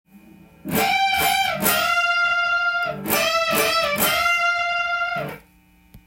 Gミクソリディアンスケールを例にフレーズを作ってみました。
③のフレーズはオクターブチョーキングを
ミクソリディアンスケールで横で動かしたものです。